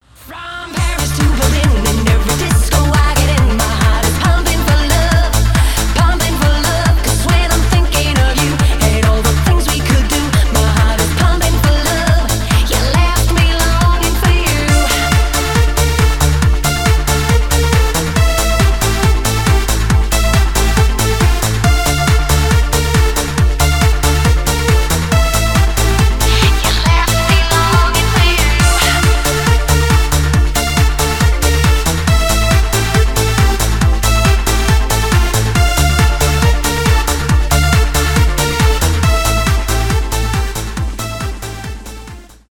euro house , евродэнс
транс